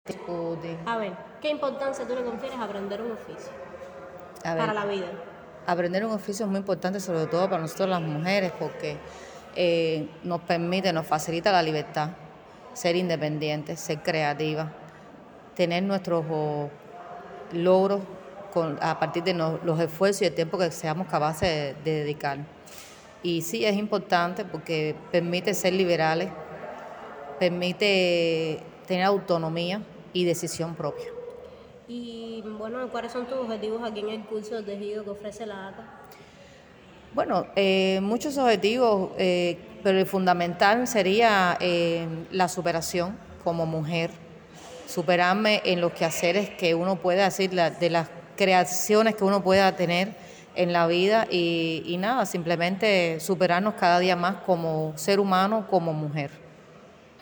sobre ello conversamos con una de las graduadas